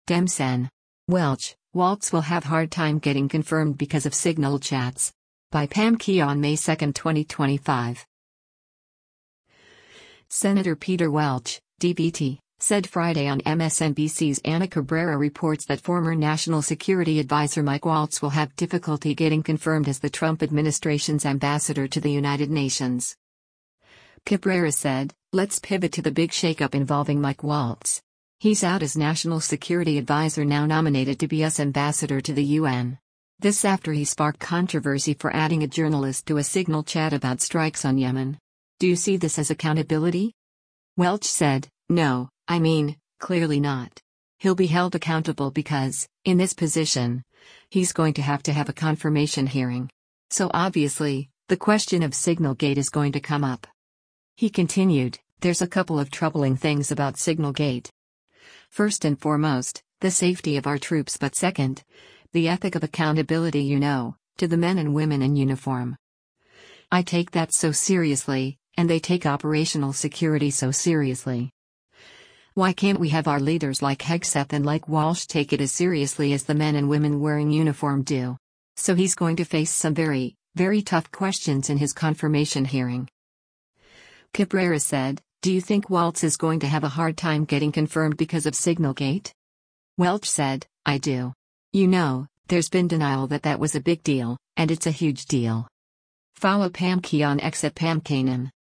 Senator Peter Welch (D-VT) said Friday on MSNBC’s “Ana Cabrera Reports” that former national security advisor Mike Waltz will have difficulty getting confirmed as the Trump administration’s ambassador to the United Nations.